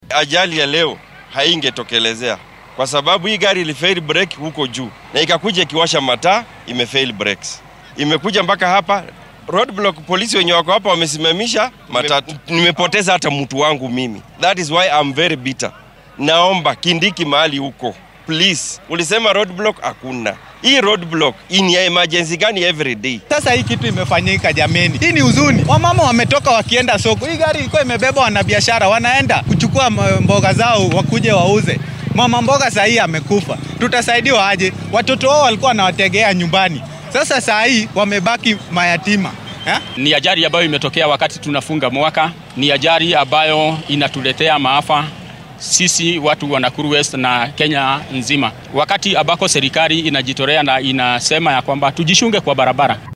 Qaar ka mid ah shacabka galbeedka Nakuru oo goobjoog u ahaa shilka ayaa ka hadlay shilka maanta dhacay iyagoo baaq u diray dowladda.
Dareenka-shacabka-ee-Shilka-Nakuru.mp3